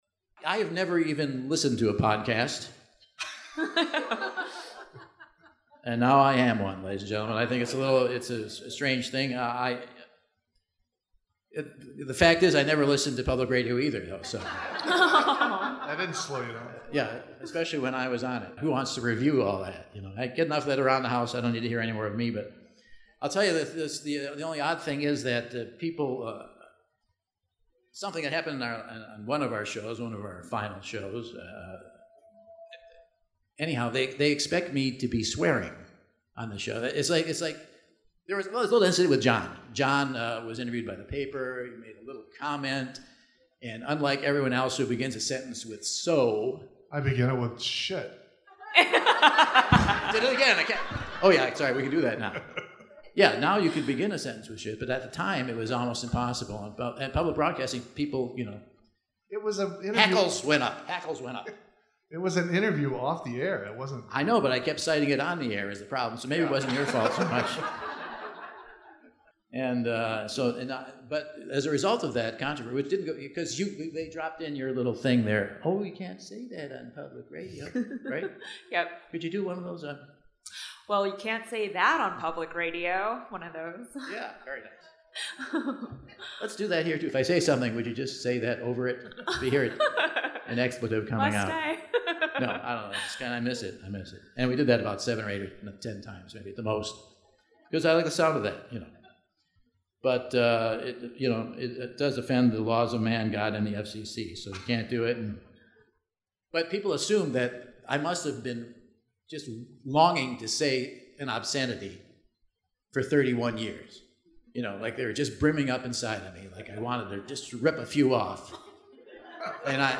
Explore posts in the same categories: comedy , iTunes , live show , podcast This entry was posted on December 9, 2016 at 2:09 pm and is filed under comedy , iTunes , live show , podcast .